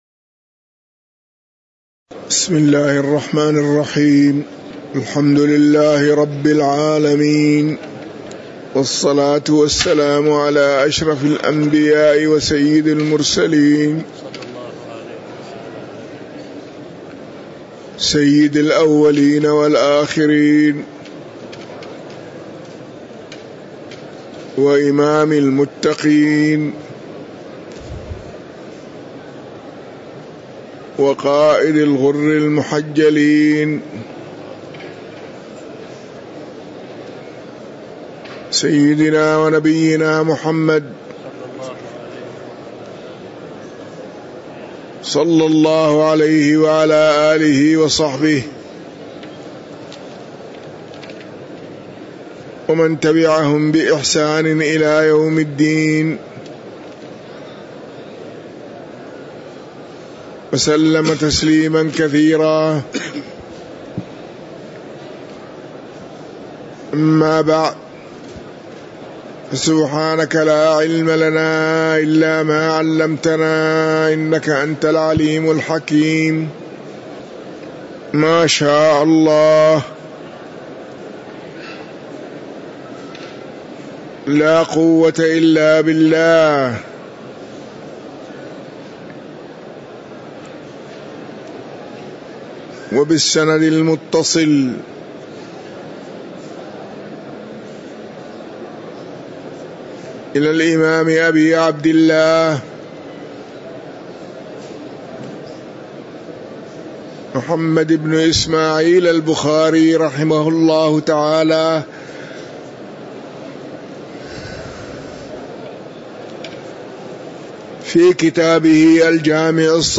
تاريخ النشر ٢٥ صفر ١٤٤٤ هـ المكان: المسجد النبوي الشيخ